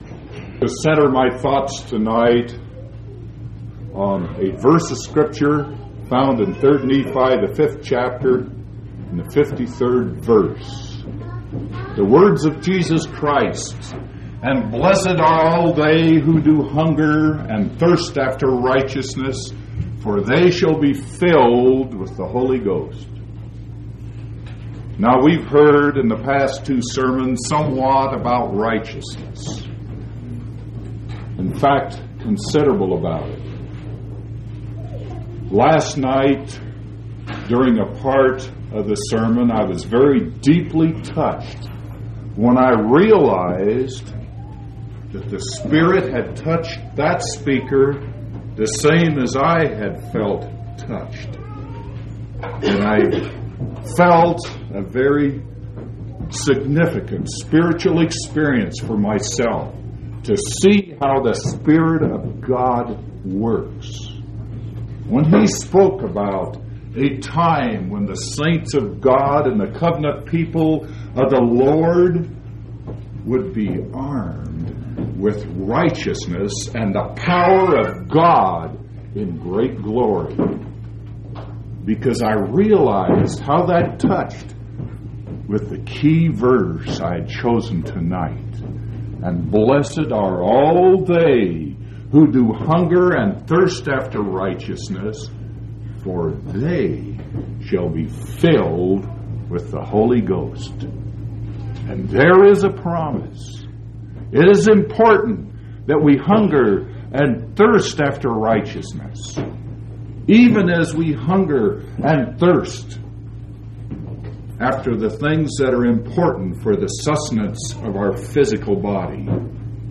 6/9/1984 Location: Colorado Reunion Event